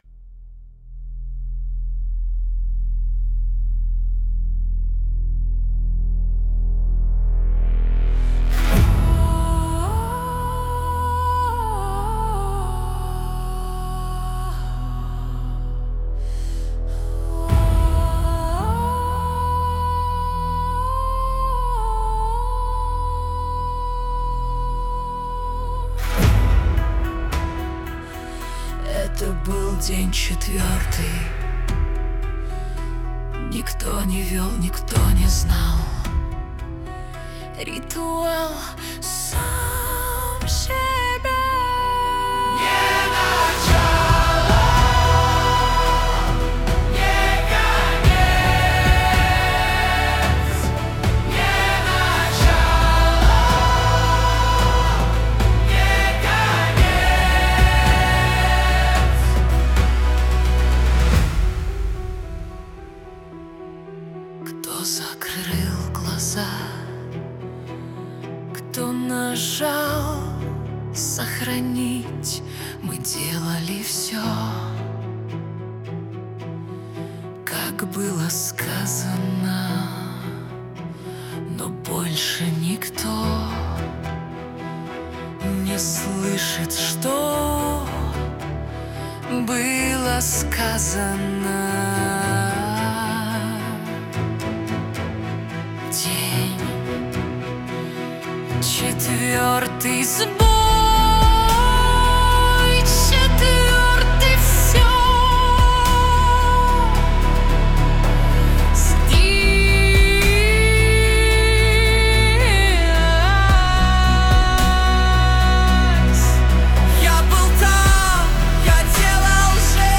Повтор, изнурение, крик без отклика.